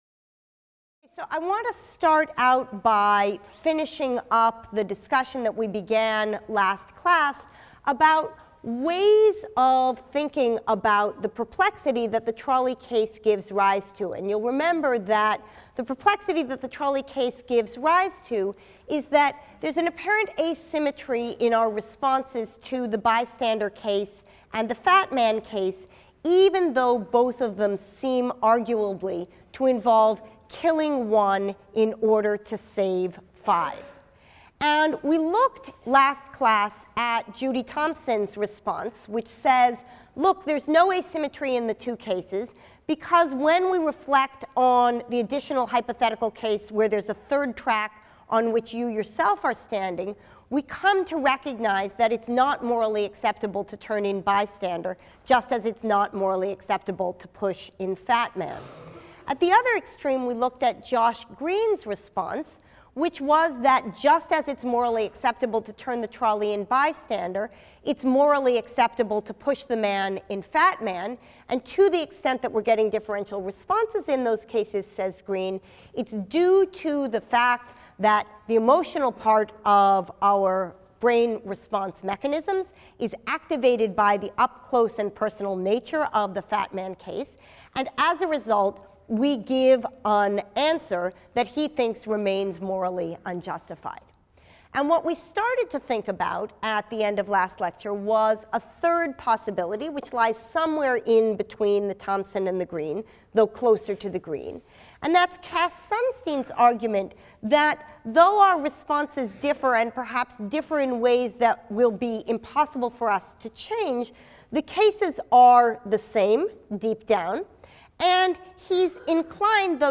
PHIL 181 - Lecture 16 - Philosophical Puzzles | Open Yale Courses